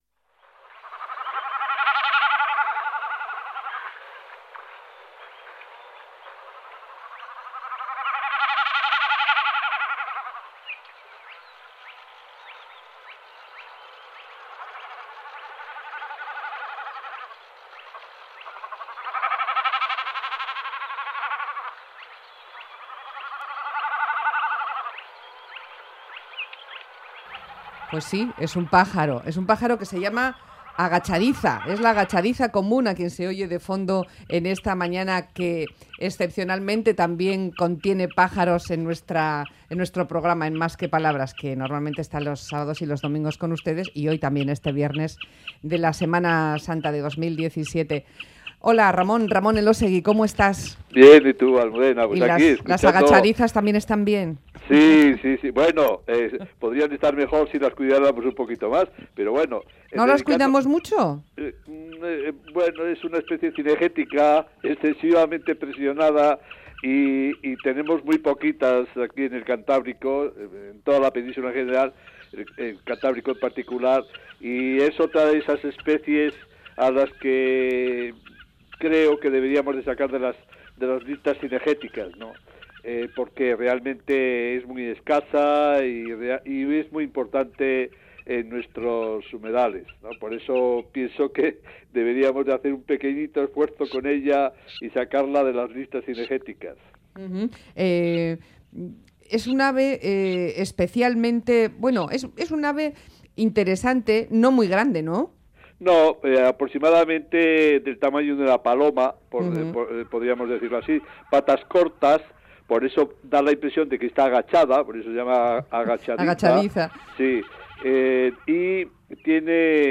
La agachadiza común